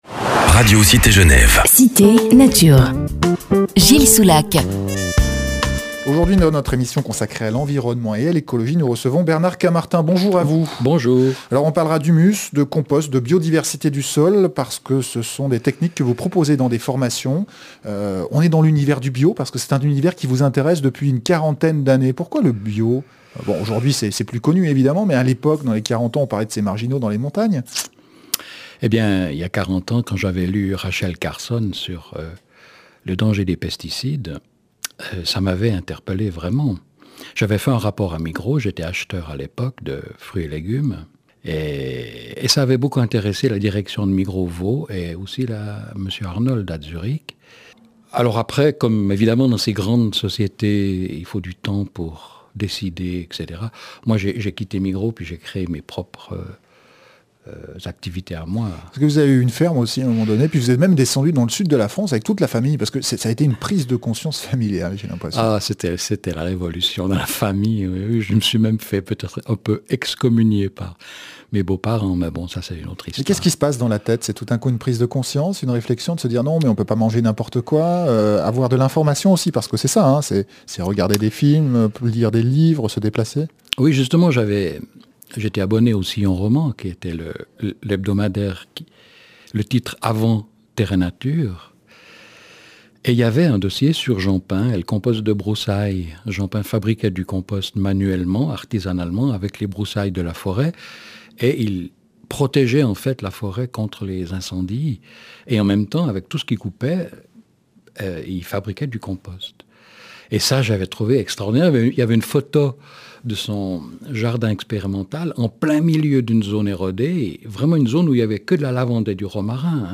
Entretien, résumé en quelques minutes d’une quarantaine d’années d’activités et d’expériences dans le domaine de la régénération des sols, la lutte contre l’érosion et la désertification, la prévention des famines et des sécheresses.